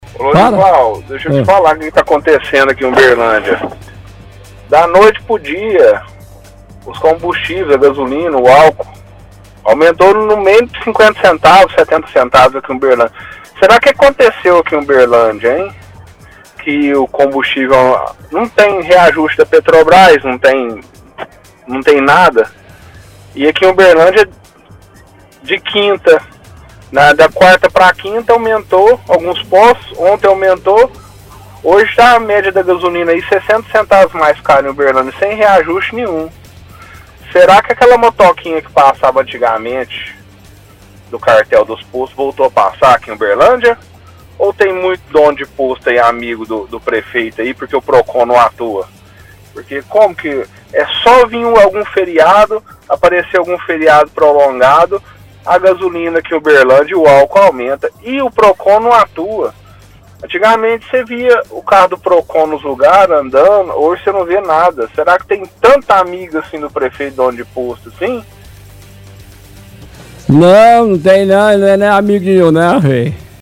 – Ouvinte relata aumento de preço de combustíveis e reclama da falta de fiscalização do Procon.